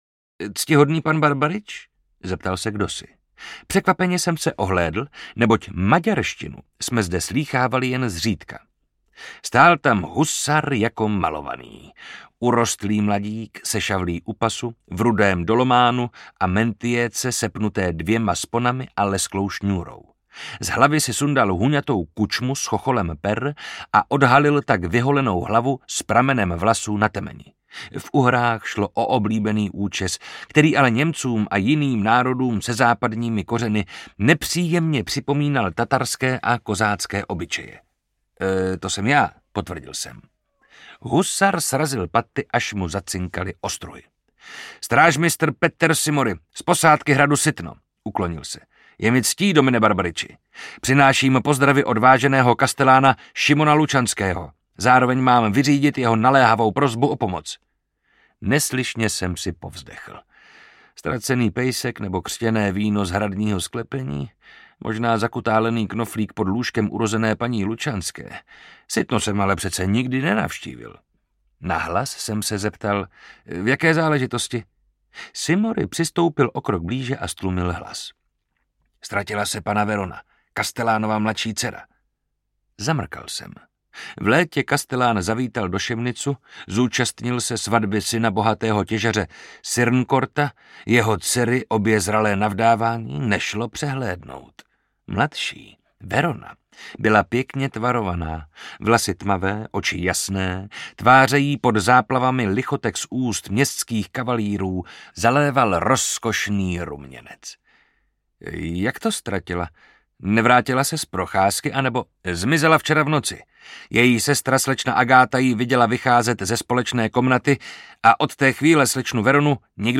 Vlk a dýka audiokniha
Ukázka z knihy
Čte Marek Holý.
Vyrobilo studio Soundguru.